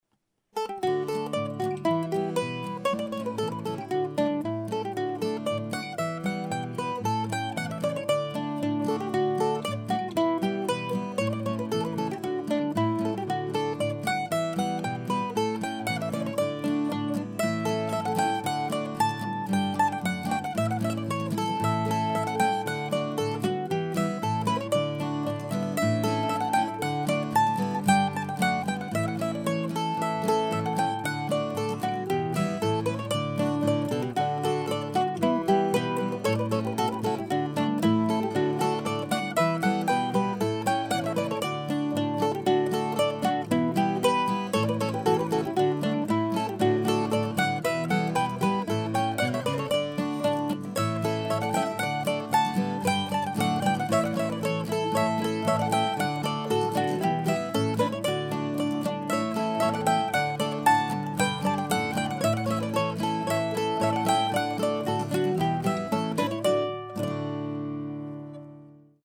The mandolin featured here is an old Gibson snakehead A-jr. and the guitar is a recently made Martin 000-15M.